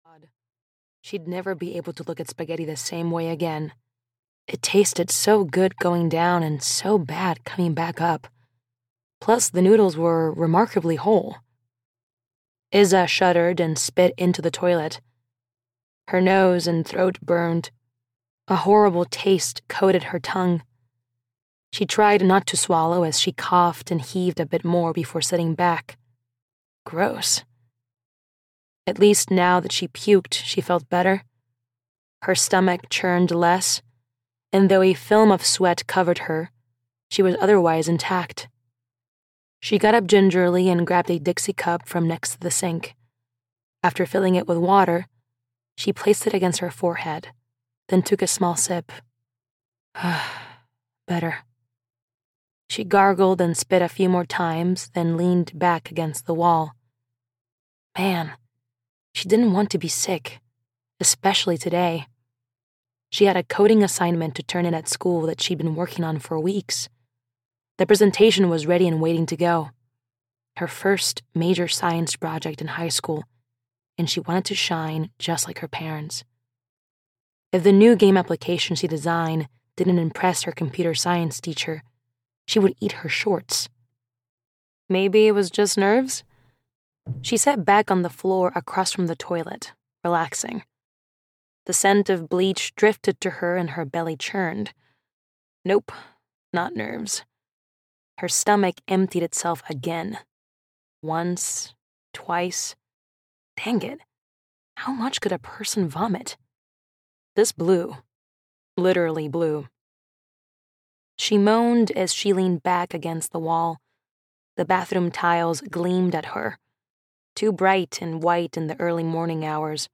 Blood Will Tell (EN) audiokniha
Ukázka z knihy